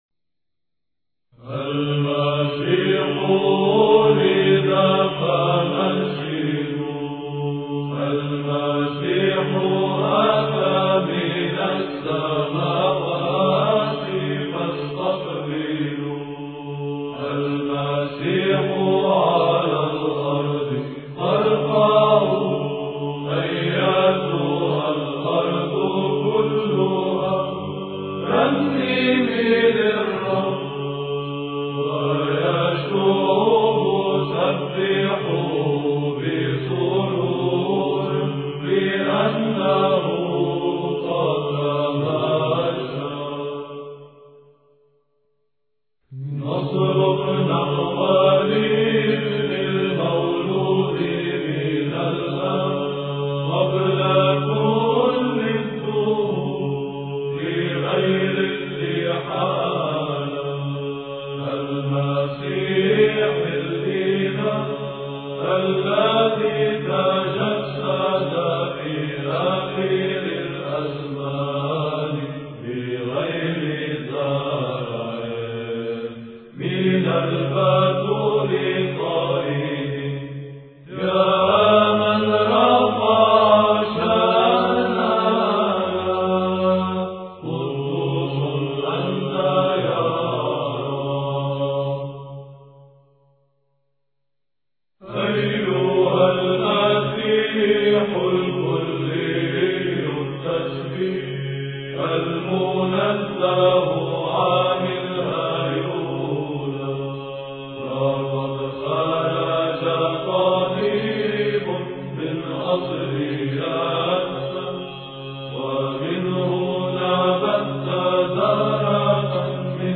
بيزنطية:
Christmas_Chants.mp3